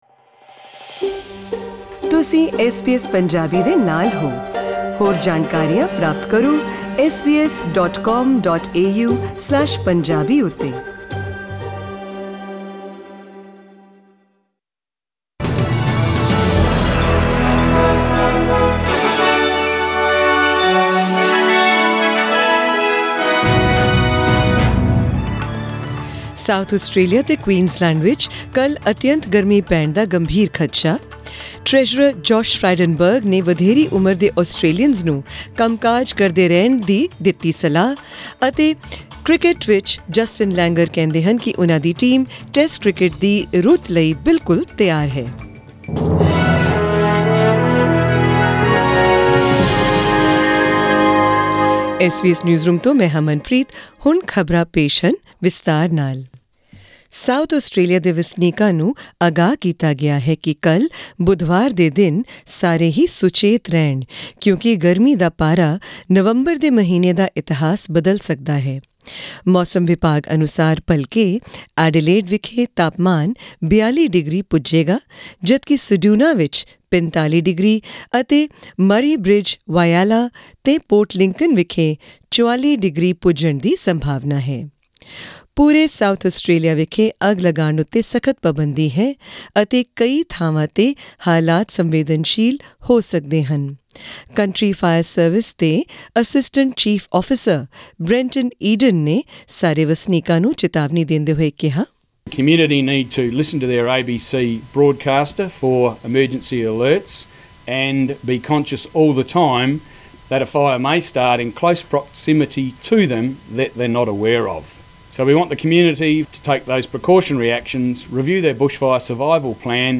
Here are the headlines in tonight’s news bulletin: South Australia and Queensland braces for heatwave conditions...